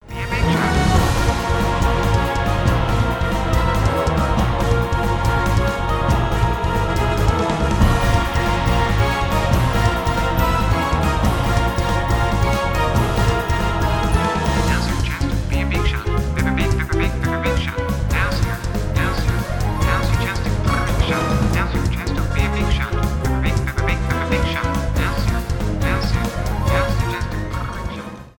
электронные